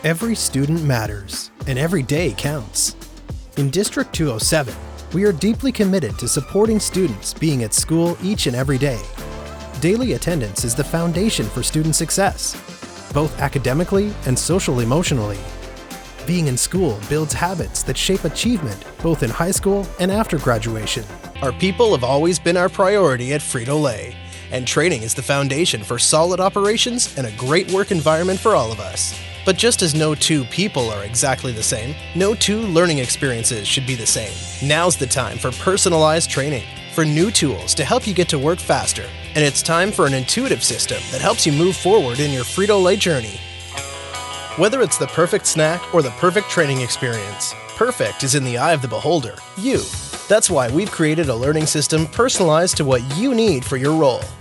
Corporate Videos/Training/E-Learning
Canadian, American Generic
Studio Specs: Soundproof, professional-grade setup
A young, energetic, and adaptable voice